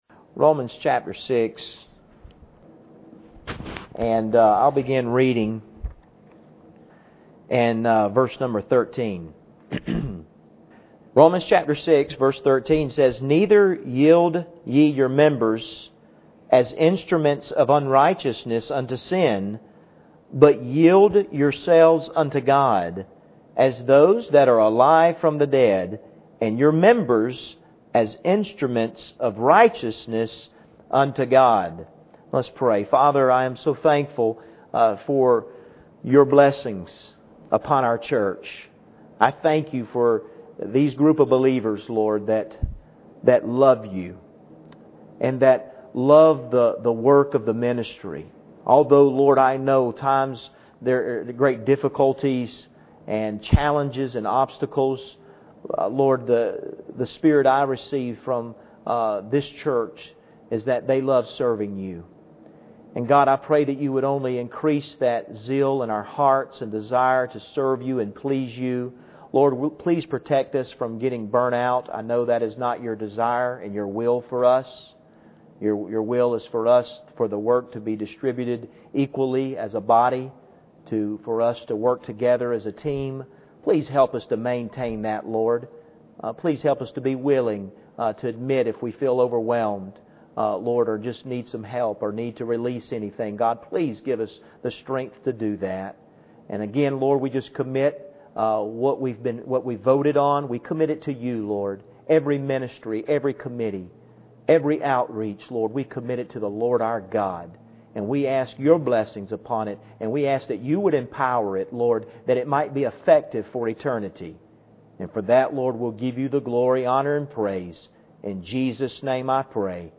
Passage: Romans 6:13 Service Type: Wednesday Evening